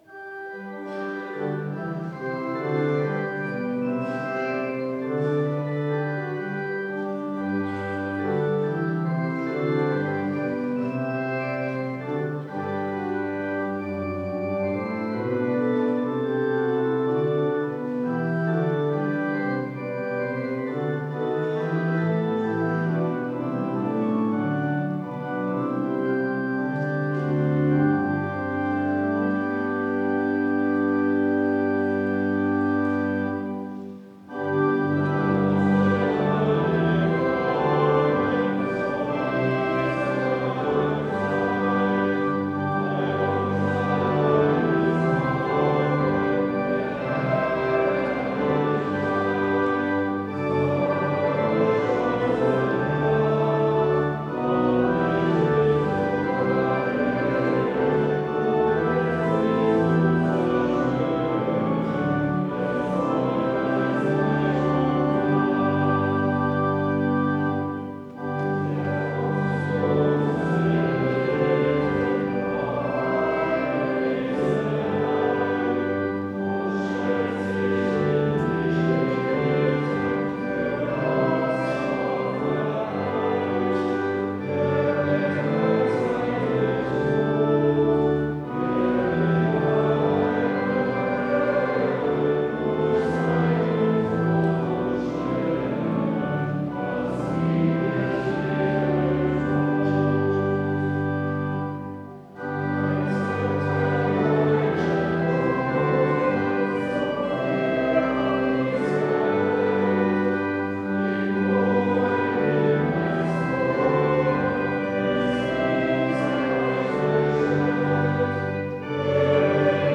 Audiomitschnitt unseres Gottesdienstes vom 4.Advent 2025.